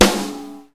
softl-hitclap2.mp3